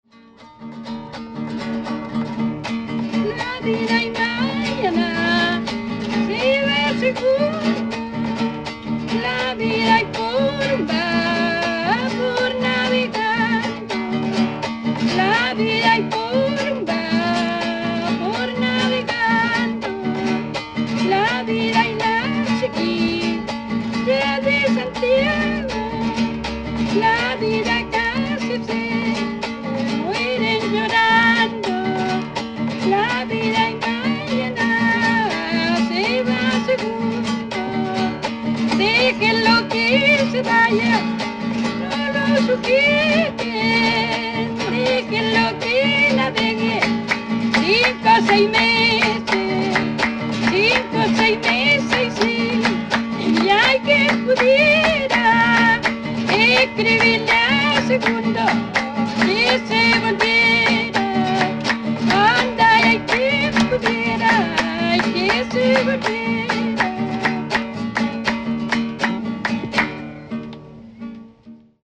Música chilena
Música tradicional
Cueca
Música folclórica